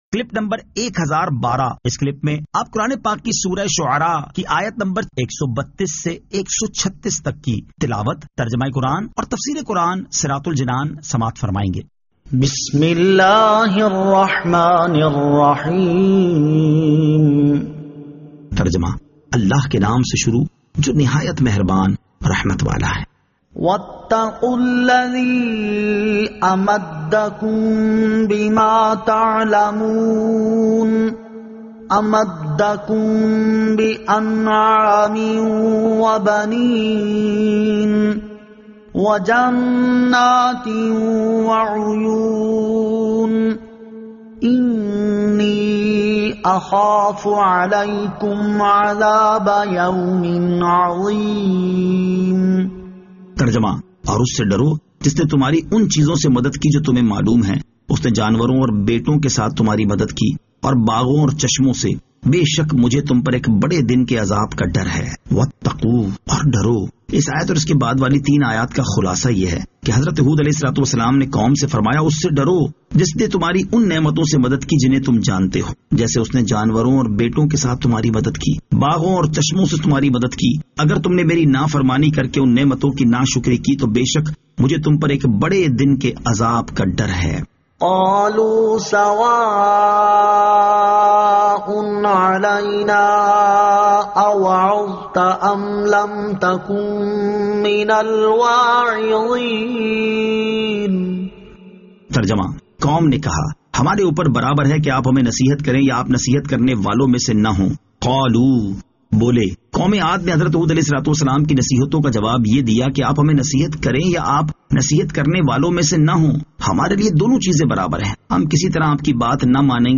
Surah Ash-Shu'ara 132 To 136 Tilawat , Tarjama , Tafseer